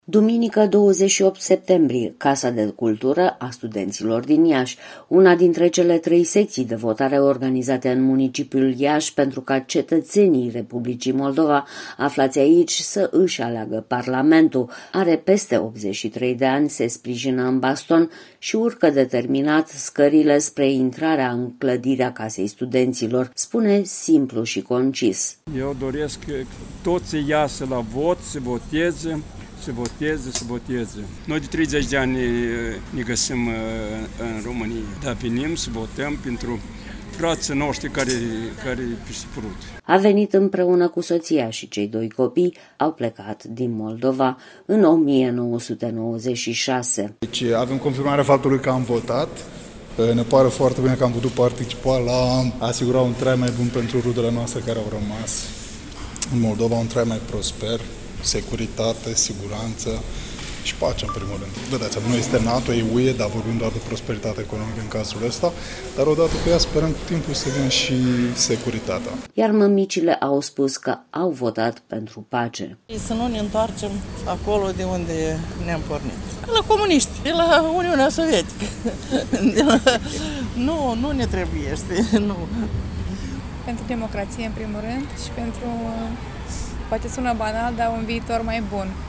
Casa de Cultură a Studenților din Iași.